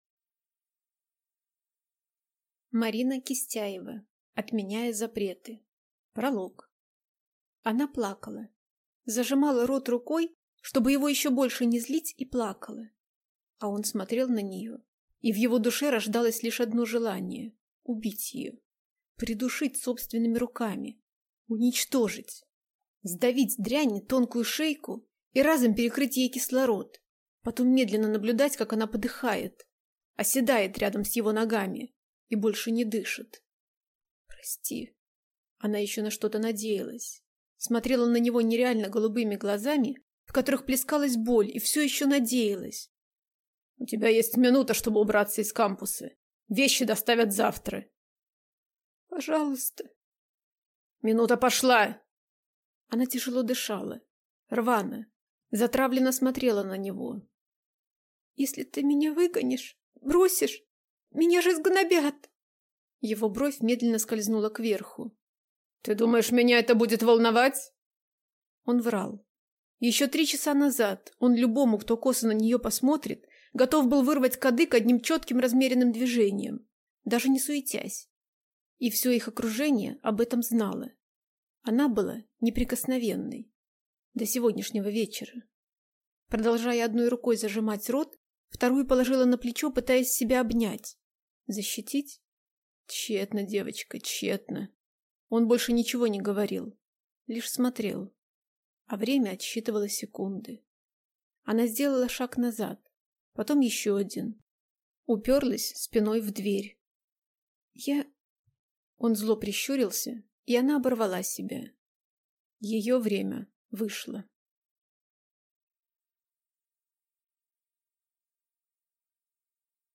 Аудиокнига Отменяя запреты | Библиотека аудиокниг